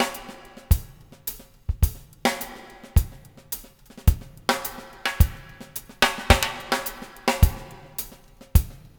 CLB DRUMS -R.wav